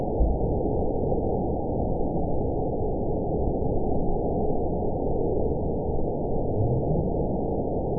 event 920499 date 03/27/24 time 22:19:10 GMT (1 year, 2 months ago) score 9.03 location TSS-AB07 detected by nrw target species NRW annotations +NRW Spectrogram: Frequency (kHz) vs. Time (s) audio not available .wav